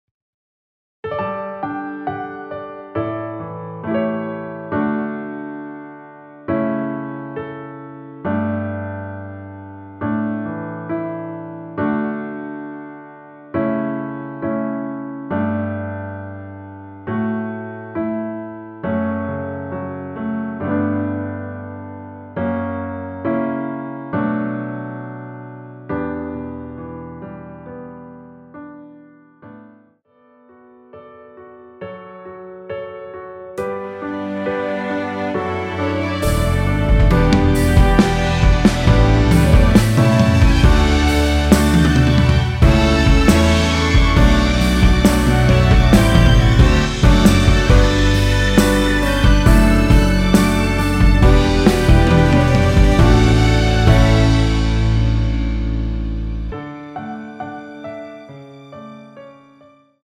원키에서(-1)내린 MR입니다.
D
앞부분30초, 뒷부분30초씩 편집해서 올려 드리고 있습니다.